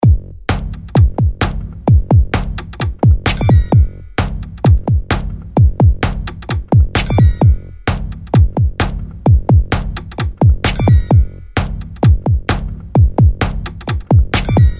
beatbox 130bpm simple loop
描述：me beatboxing at 130bpm, typical beat, loopable Recorded with Sony HDR PJ260V then edited using Audacity
标签： loop percussion beat human 130 looping drums bpm beatbox 130bpm rhythm drumloop
声道立体声